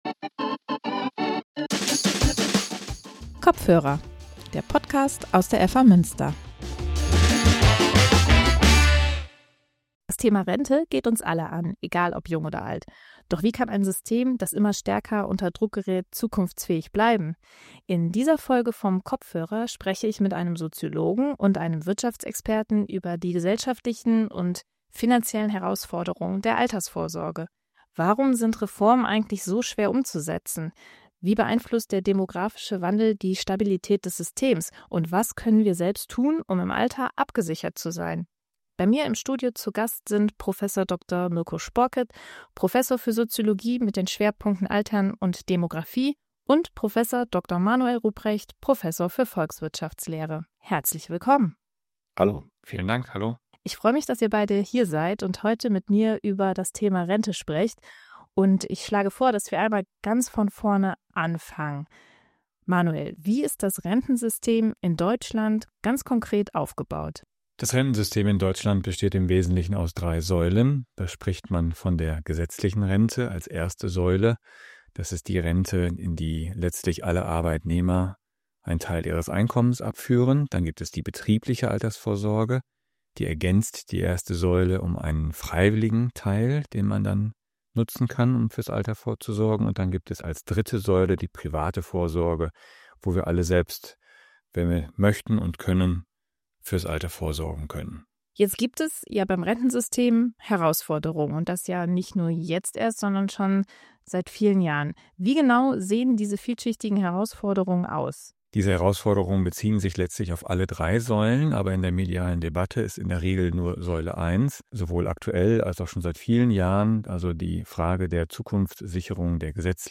In dieser Folge vom „Kopfhörer“ diskutieren ein Soziologe und ein Wirtschaftsexperte über die gesellschaftlichen und wirtschaftlichen Herausforderungen der Alter...